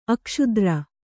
♪ akṣudra